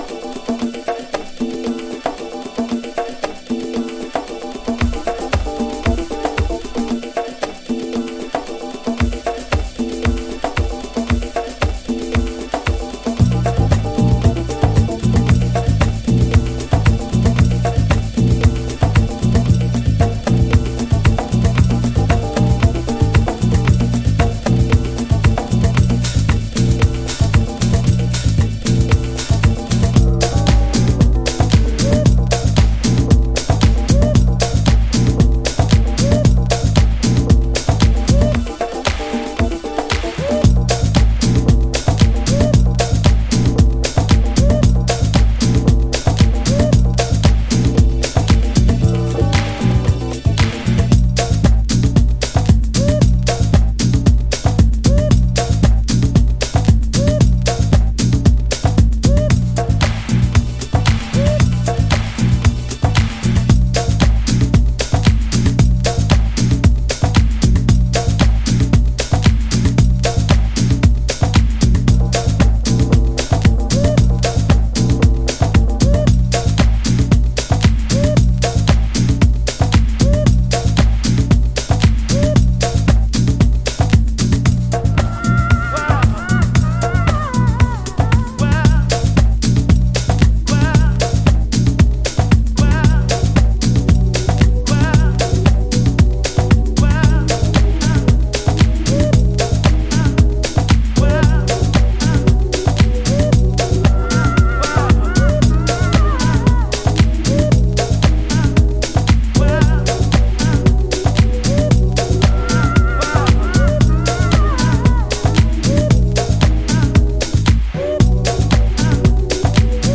House / Techno
ディスコサンプルをタフなハウスグルーヴに落とし込んだデトロイトハウススタイル。